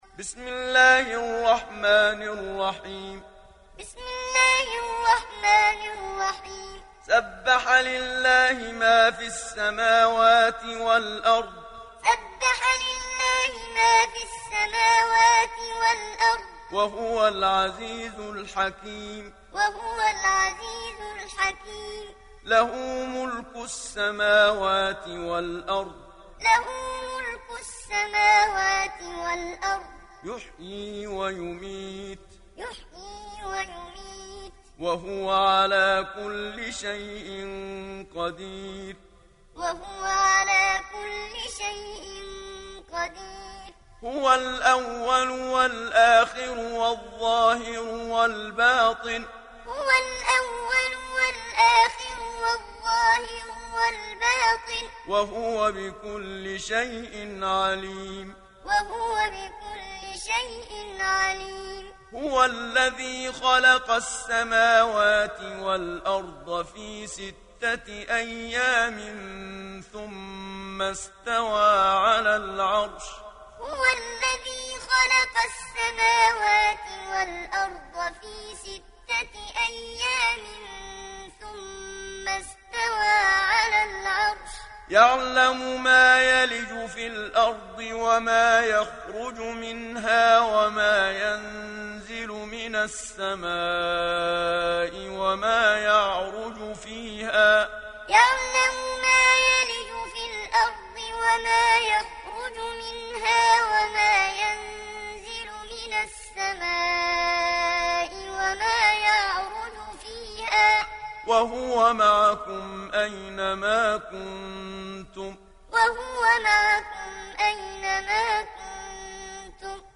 Hafs an Asim
Muallim